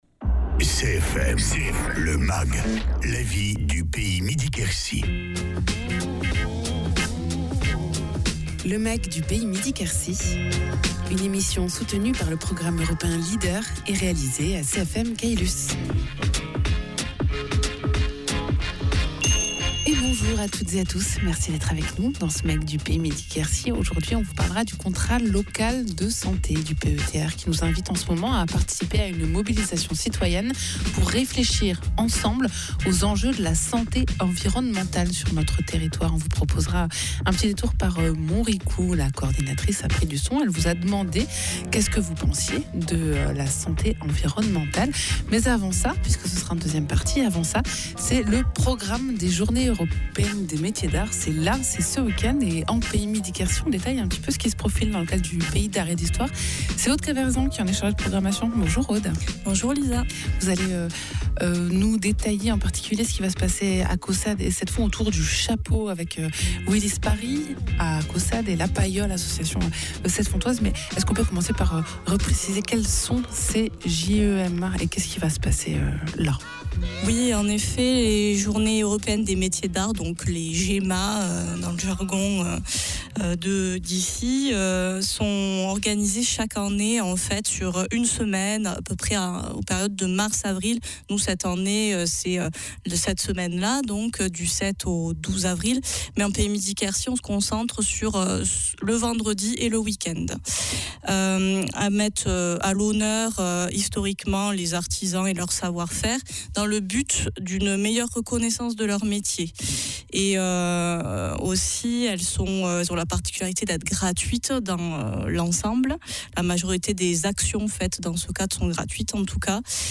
Portes ouvertes, démonstrations, ateliers, expositions…Tout un programme dédié aux rencontres et aux échanges pour tisser des liens entre les artisans d’art du territoire et ses habitants. Egalement dans ce mag, on parle santé environnementale avec le Contrat Local de santé du PETR Midi-Quercy : témoignages à Montricoux dans le cadre d’un parcours citoyen.